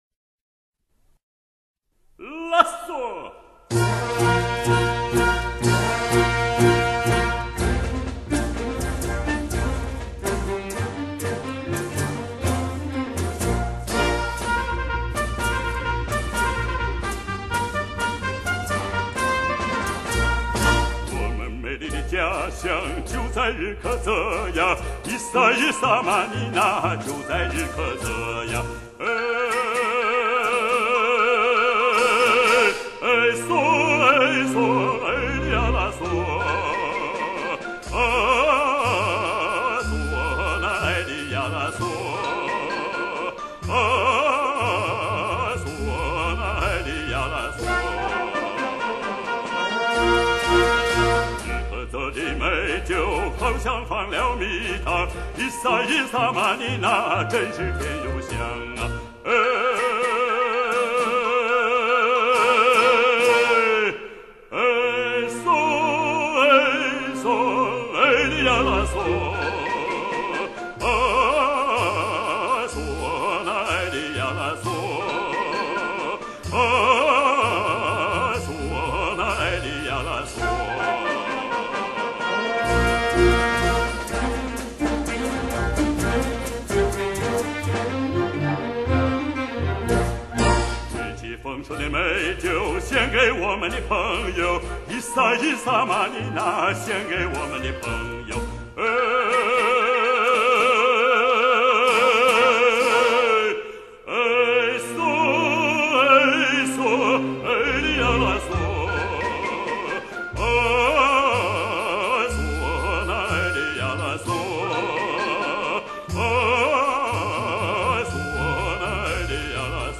[6/11/2009]著名男低音歌唱家温可铮演唱西藏民歌《我们美丽的家乡=我家在日喀则》 激动社区，陪你一起慢慢变老！